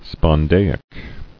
[spon·da·ic]